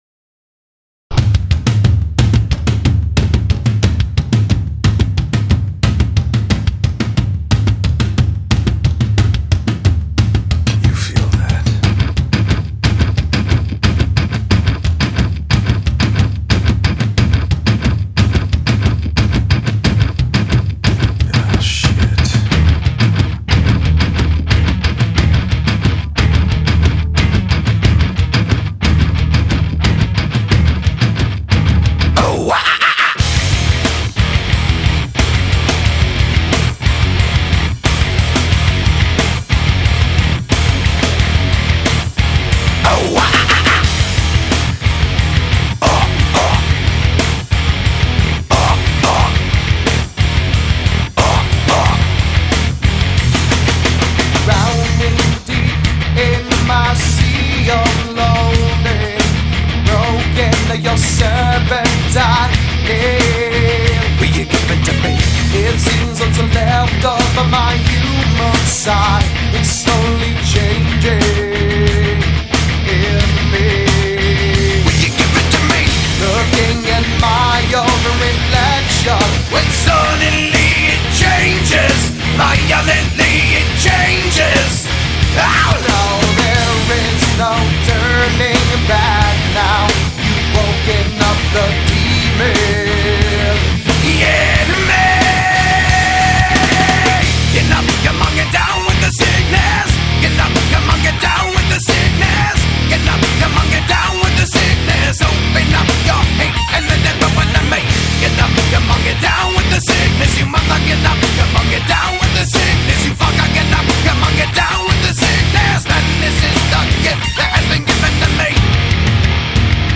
Жанр: alternativemetal